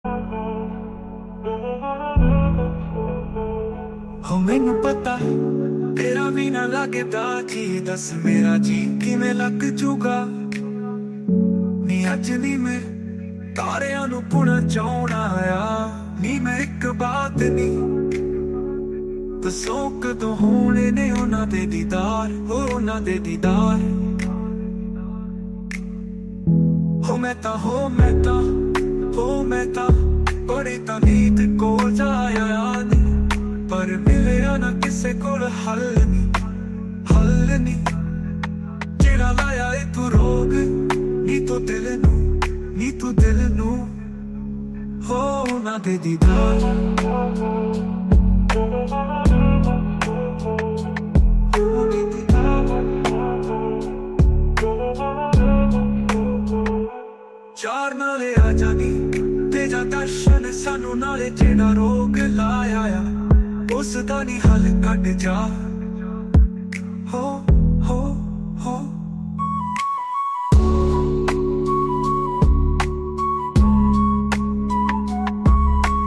Category: Single Songs